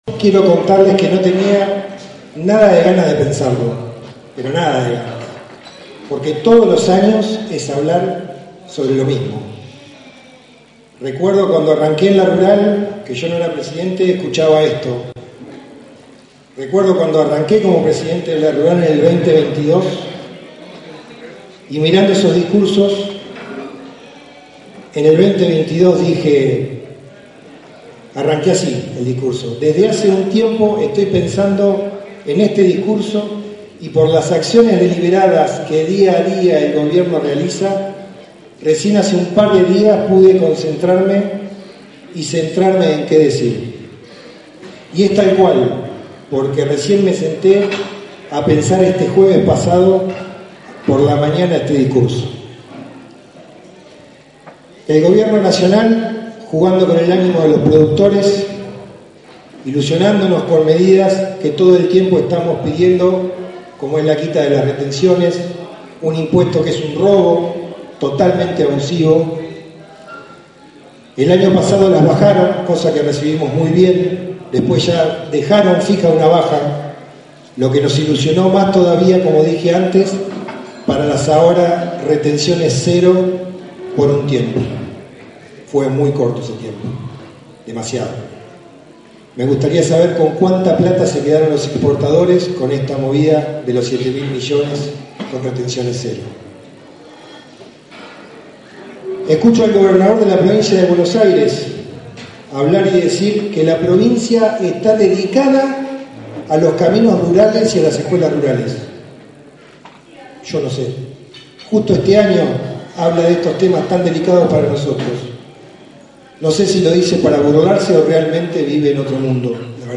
Difundimos dos de los discursos pronunciados en la inauguración de la expo rural 2025.